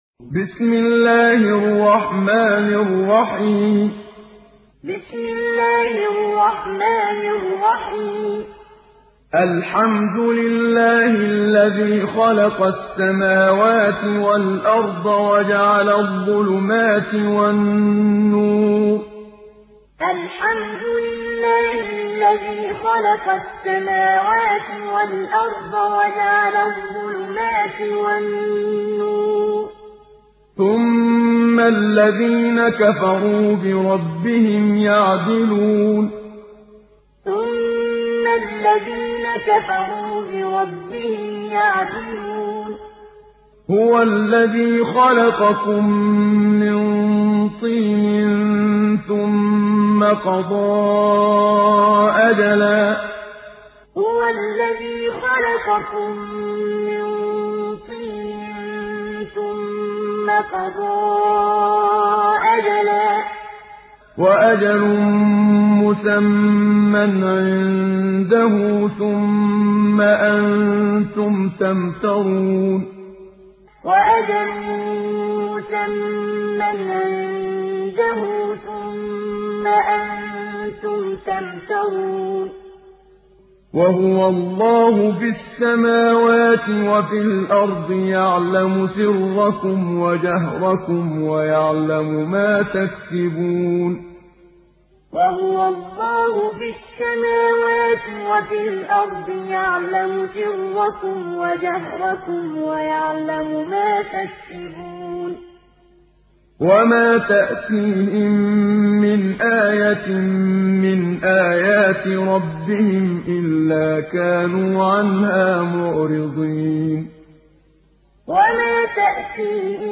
Muallim